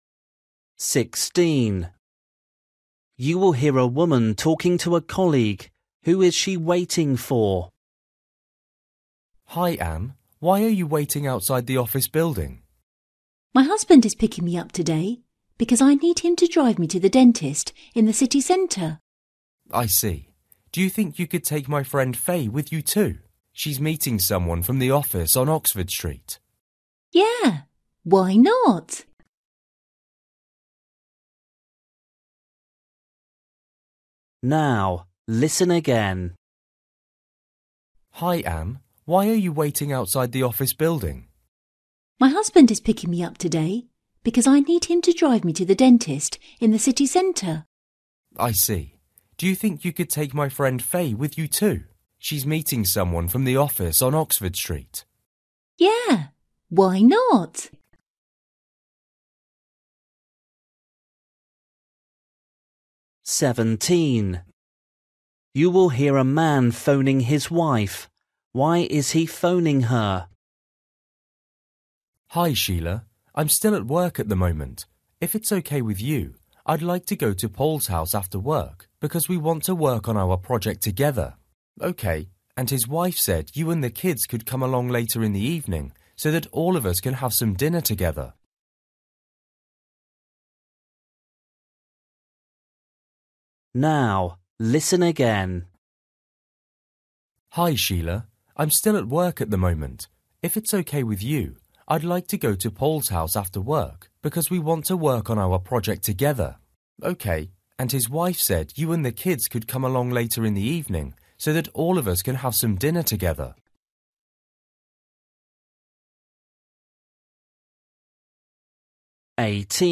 Listening: everyday short conversations
16   You will hear a woman talking to a colleague. Who is she waiting for?
17   You will hear a man phoning his wife. Why is he phoning her?
19   You will hear two friends talking about a group tour. Why will they book a Monday tour?